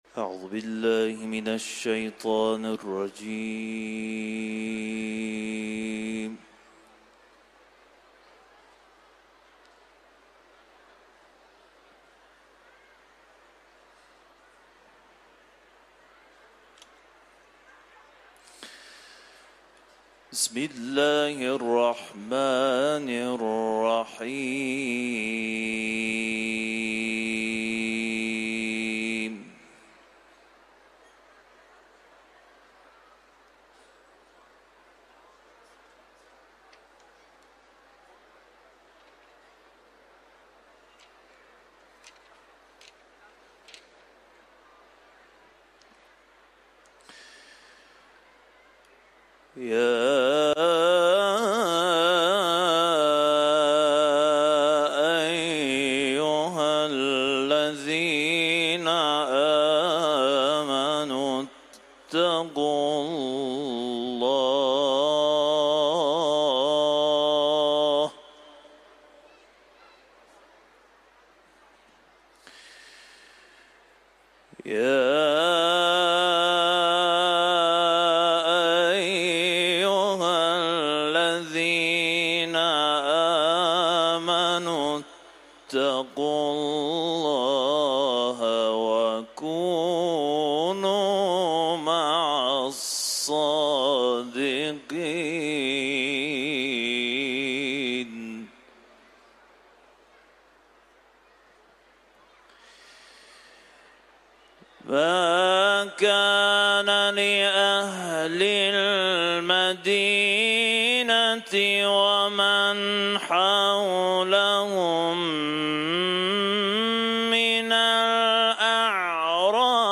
Etiketler: İranlı kâri ، Tevbe suresi ، Kuran tilaveti